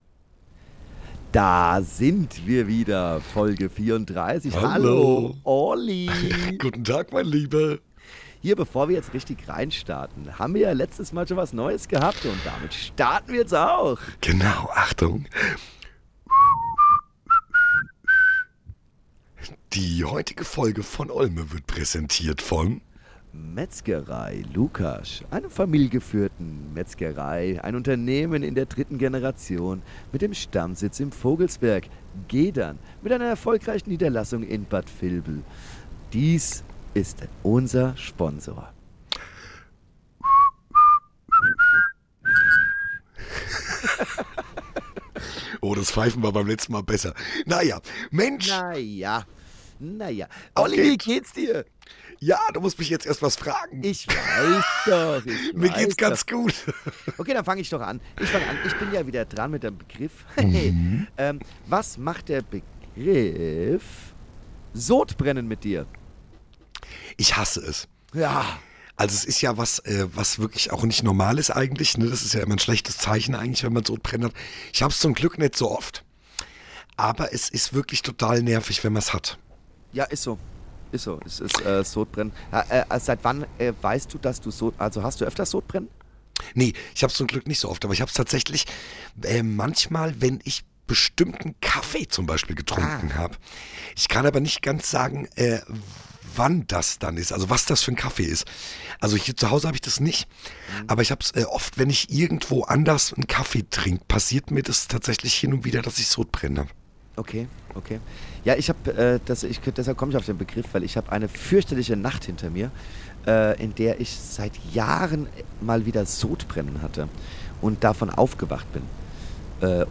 Also schnall dich an und lass dich von den beiden unterhalten – es wird witzig, nachdenklich und garantiert unvergesslich!